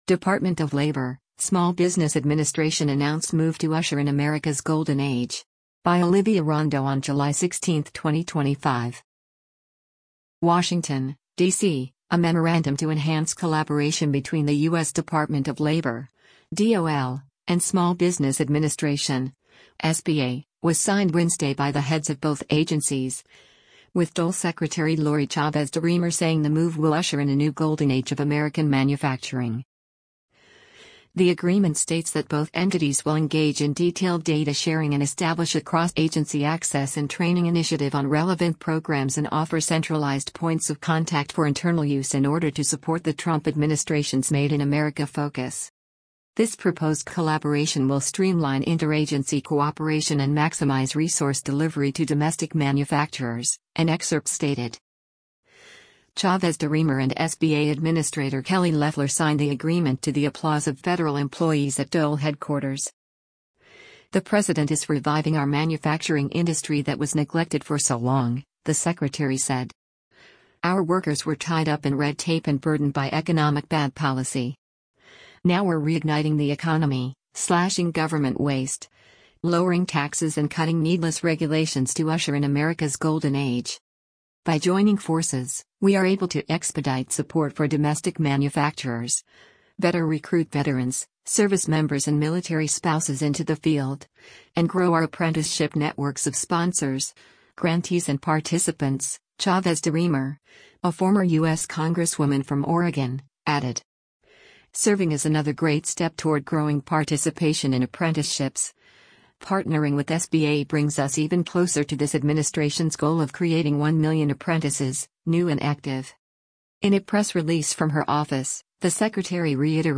Chavez-DeRemer and SBA Administrator Kelly Loeffler signed the agreement to the applause of federal employees at DOL headquarters: